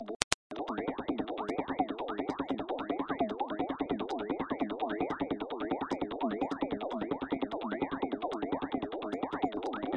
bolsa de plastico
描述：The sound of moving a plastic bag
声道立体声